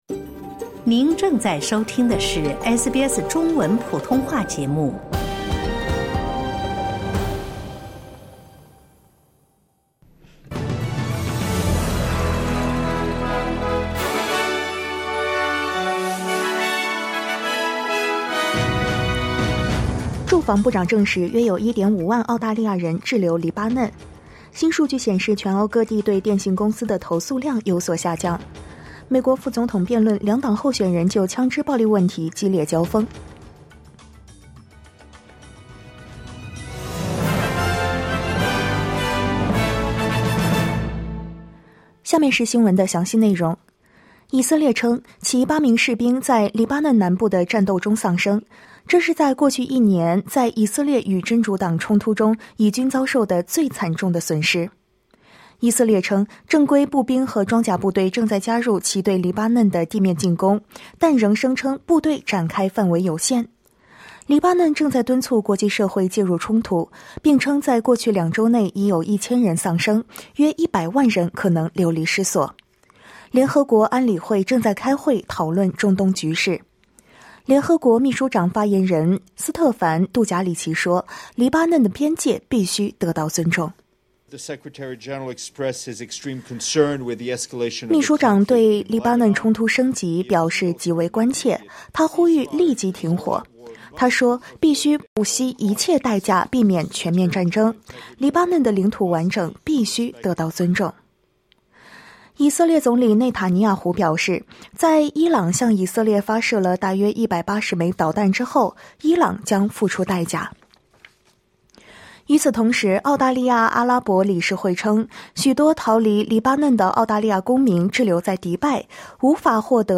SBS早新闻（2024年10月3日）